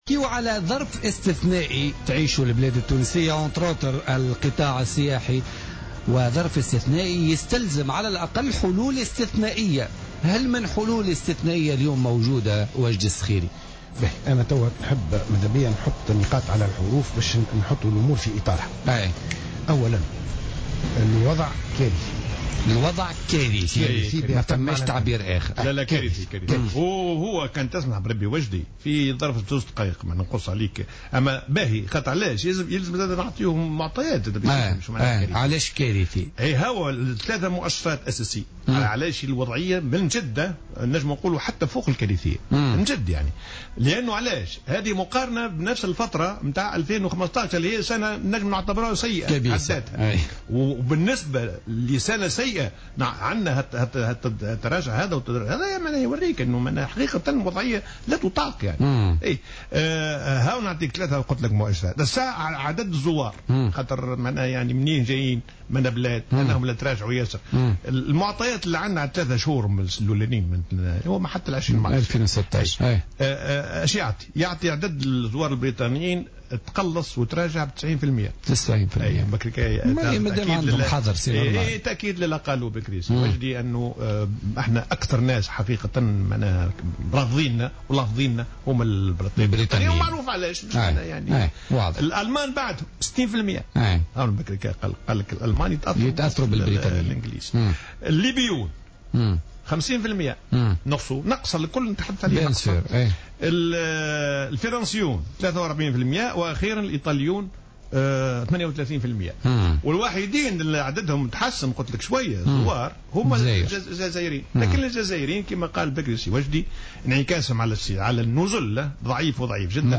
وقال الديماسي في مداخله له اليوم في برنامج "بوليتيكا" إن المؤشرات الأخيرة الخاصة بالقطاع تفيد بأن عدد السياح البريطانيين قد تقلّص خلال الأشهر الثلاثة الأولى من السنة الحالية ب90 بالمائة مقارنة بنفس الفترة من السنة الماضية، كما تراجع عدد السياح الألمان ب60 بالمائة والليبيين ب50 بالمائة، فيما تراجع عدد السياح الفرنسيين ب43 بالمائة والإيطاليين ب38 بالمائة، مقابل تحسن طفيف بالنسبة للجزائريين.